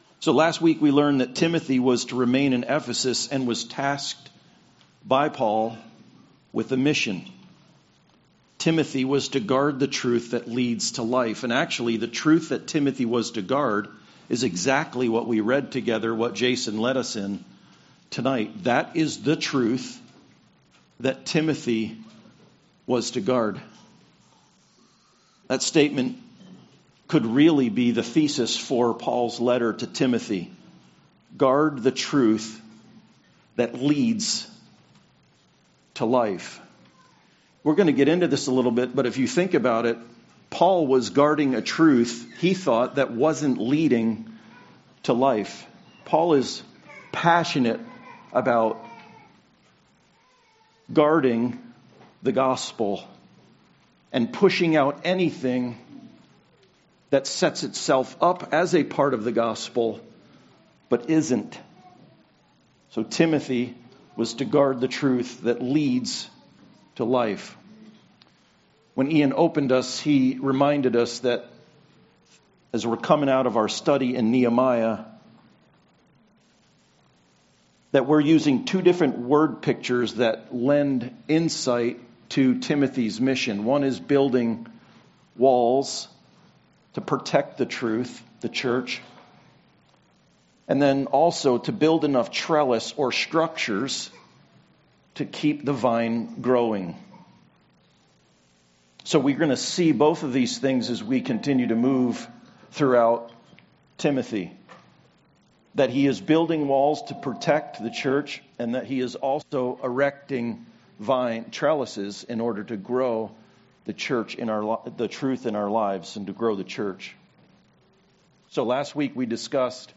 1 Timothy 1:12-20 Service Type: Sunday Service Paul to Timothy